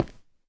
stone2.ogg